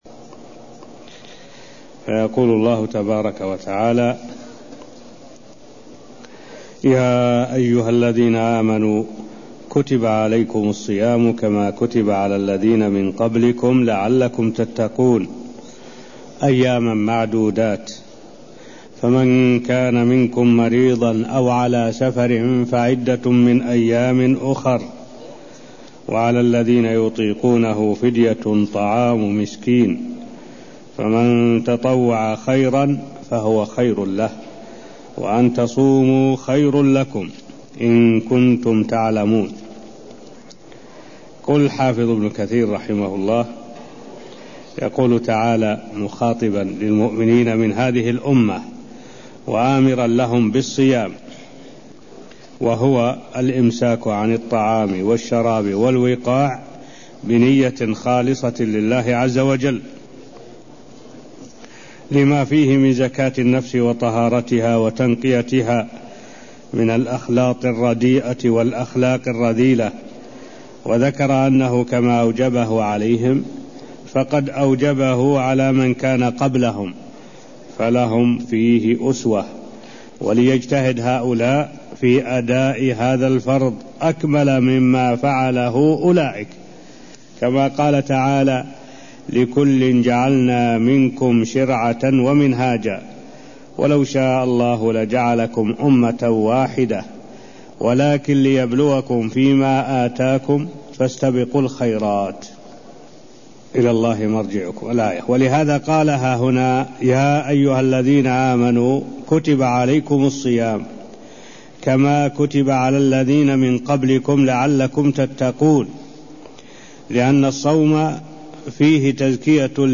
المكان: المسجد النبوي الشيخ: معالي الشيخ الدكتور صالح بن عبد الله العبود معالي الشيخ الدكتور صالح بن عبد الله العبود تفير الآيات183ـ184 من سورة البقرة (0088)